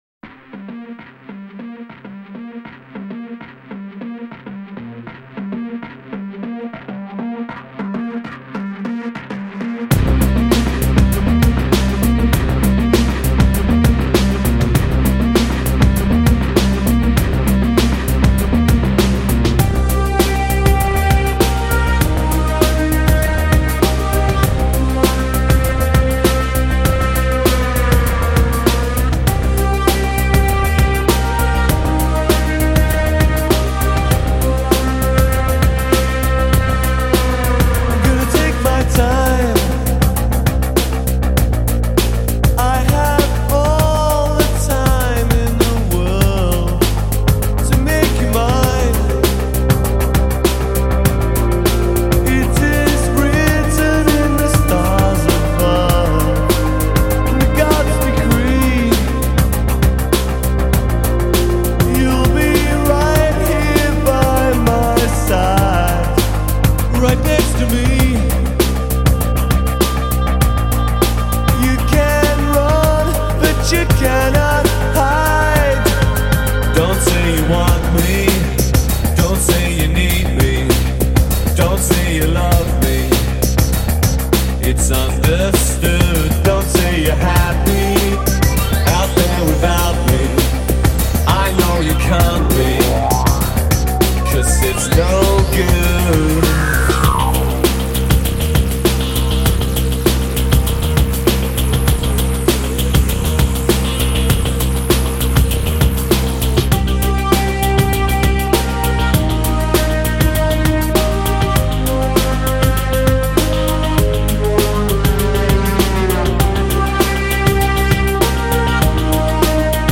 موسیقی الکترونیک